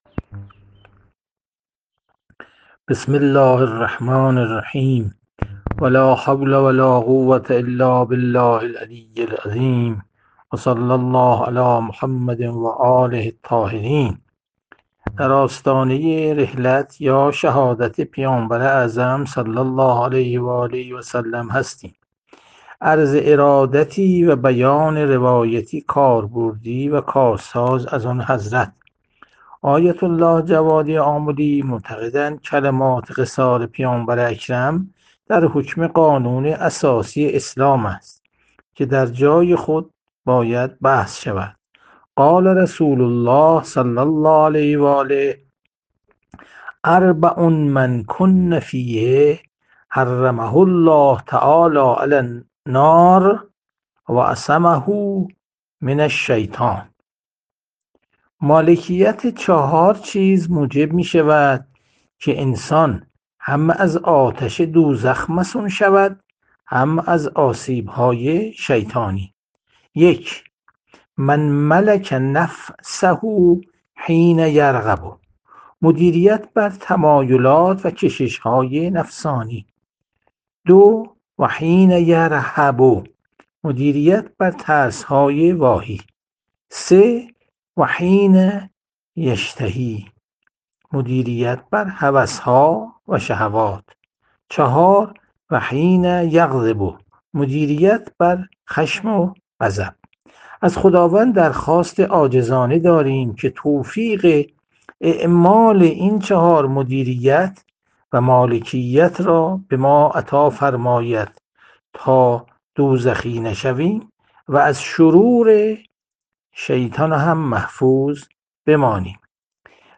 • جلسه مجازی